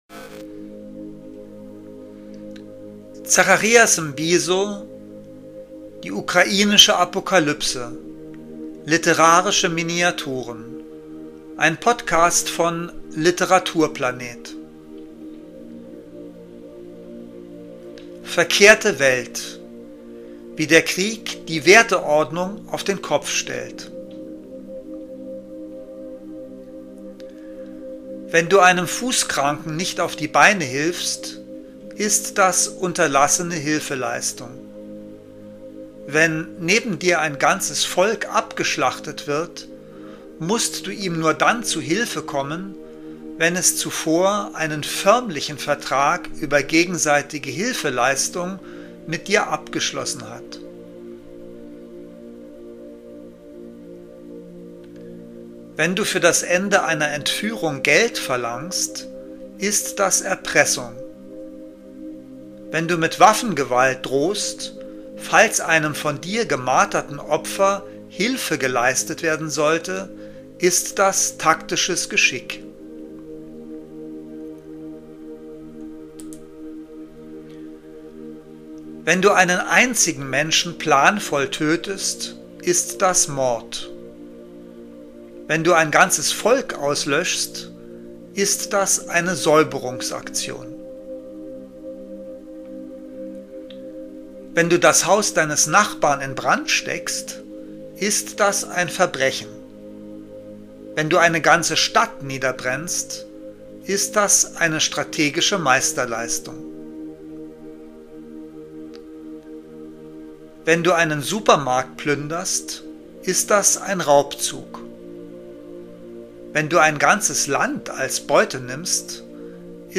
Lesungen: Literarische Miniaturen